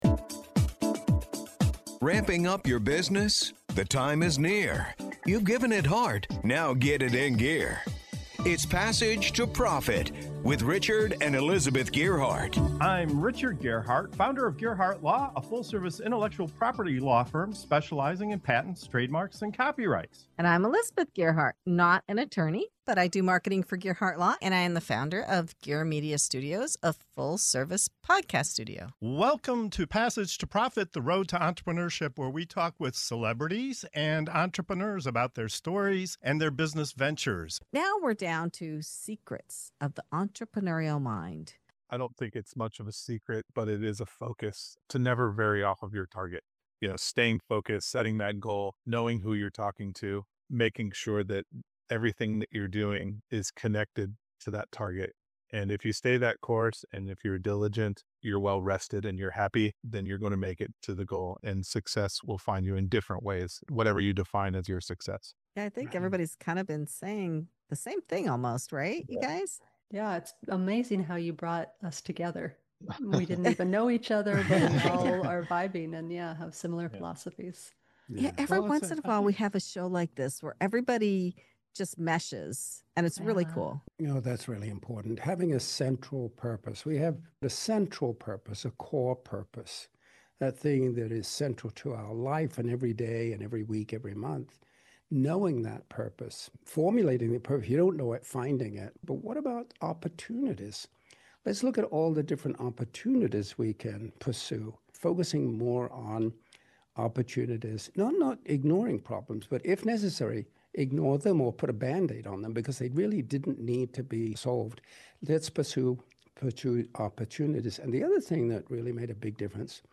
In this segment of "Secrets of the Entrepreneurial Mind" on Passage to Profit Show, our panel dives into the true “secrets” of the entrepreneurial mind—staying focused, defining purpose, seizing opportunities, and celebrating wins. From identifying what’s working to rewarding those who make success possible, you’ll hear powerful insights on how focus and recognition fuel growth, keep teams motivated, and turn challenges into opportunities.